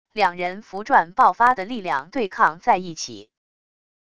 两人符篆爆发的力量对抗在一起wav音频